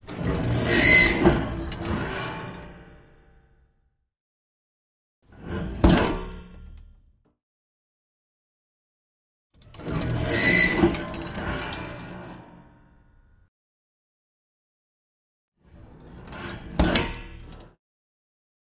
Springs, Bed, Chest, Open, Close x4